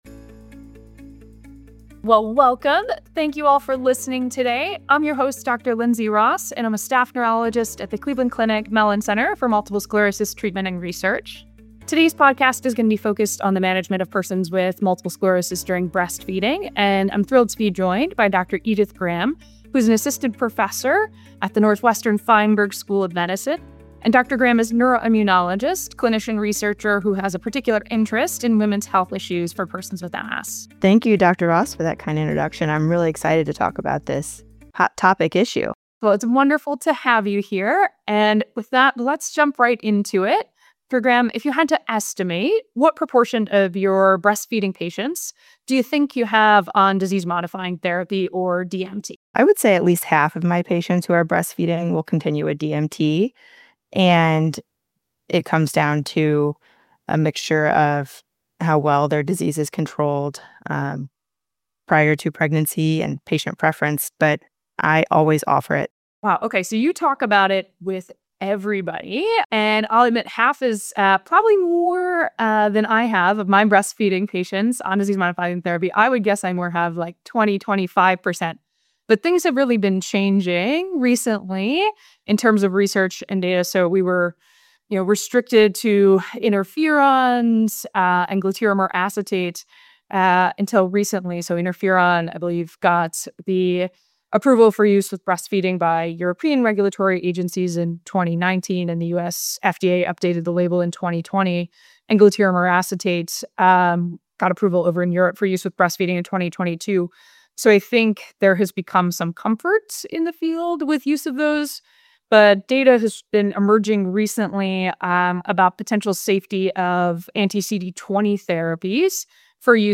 in-depth discussion on managing multiple sclerosis during breastfeeding. The conversation examines how postpartum care decisions are evolving as more safety, pharmacokinetic, and infant outcome data become available, particularly around the use of disease-modifying therapies during lactation.